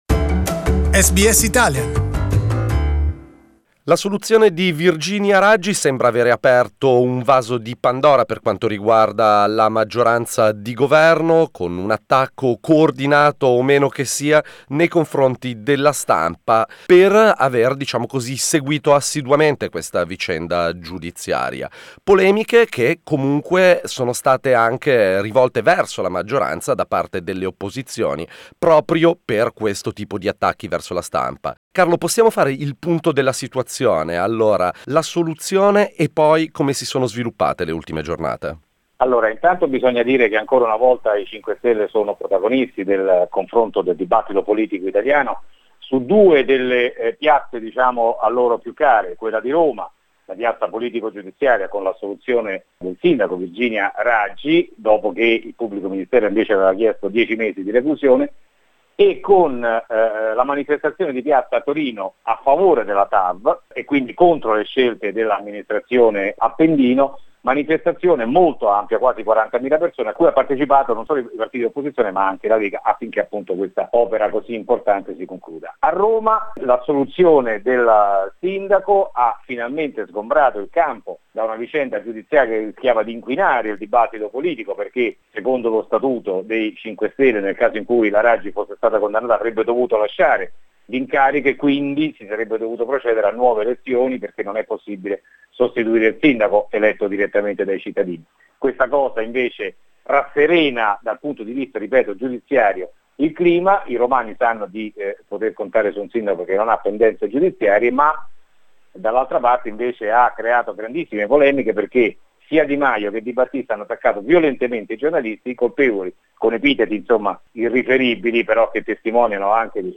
Rome correspondent